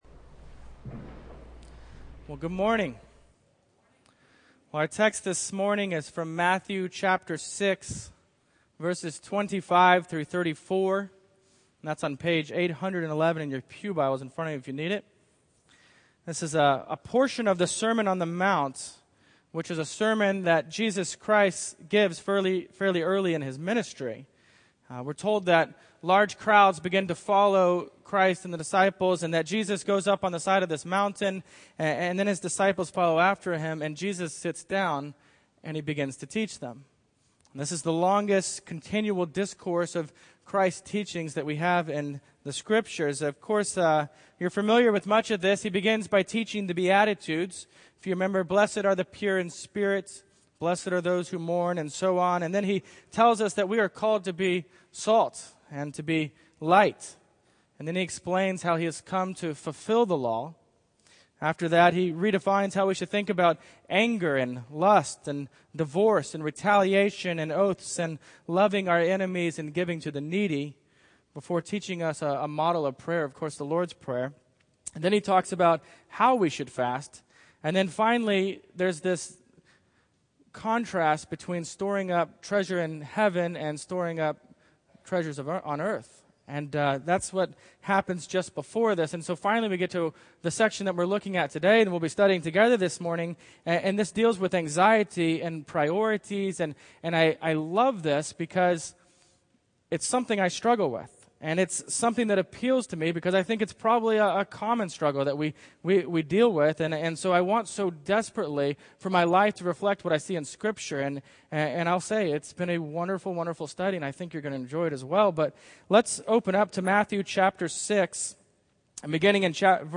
Matthew 6:25-34 Service Type: Morning Worship I. Our Heavenly Father Will Provide